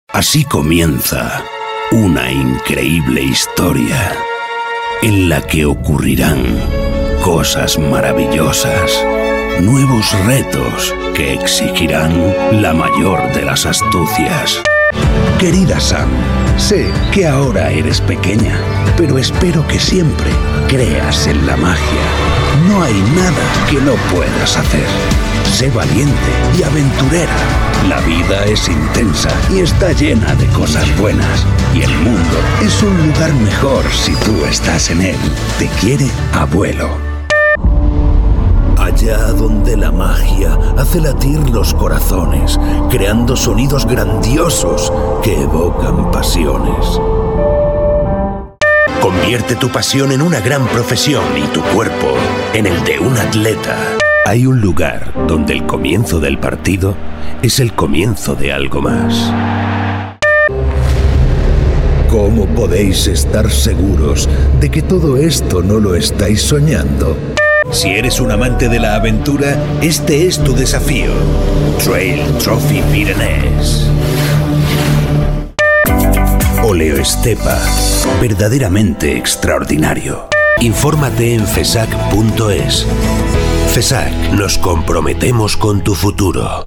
Spanish Voice Over